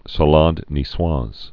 (sä-läd nē-swäz)